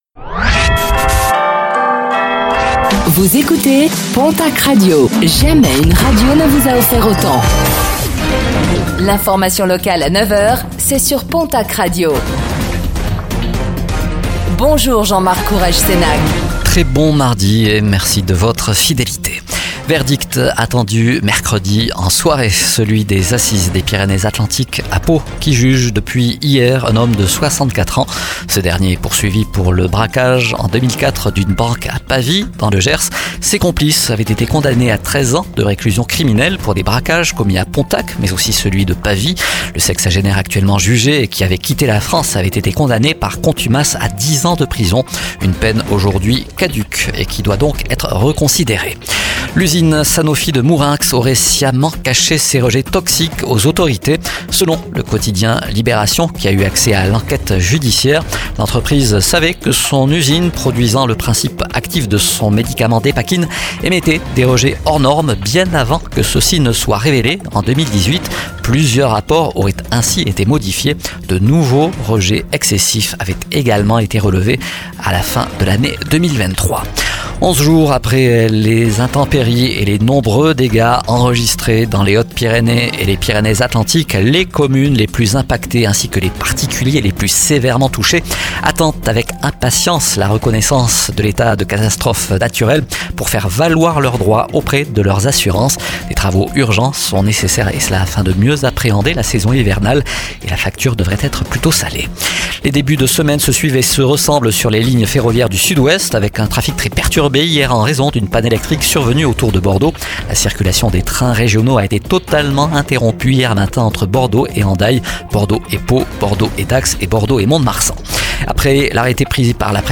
Infos | Mardi 17 septembre 2024